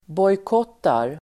Uttal: [båjk'åt:ar (el. ²b'åj:-)]